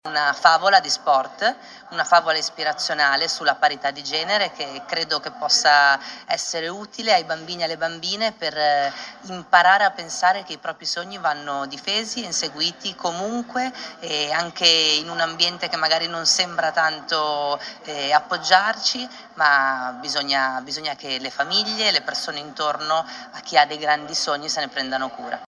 In Sardegna un progetto contro la violenza verbale nello sport. Il servizio